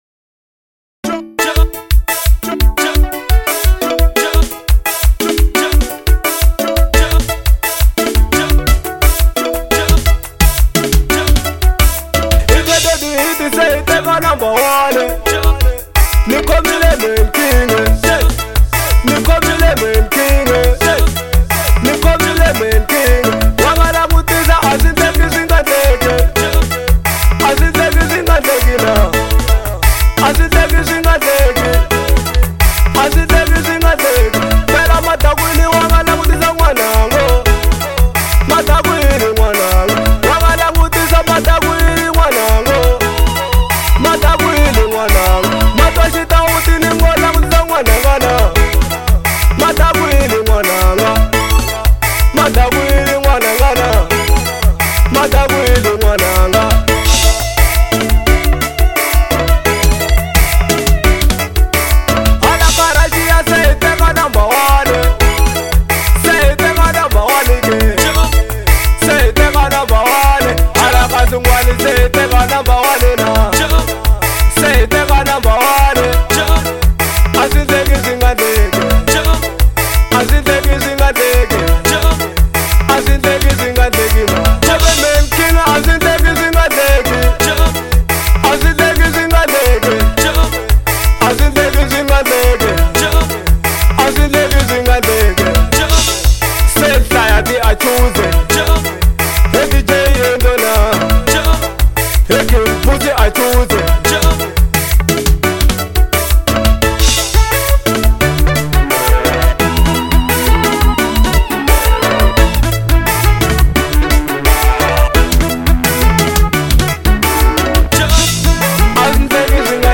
04:05 Genre : Xitsonga Size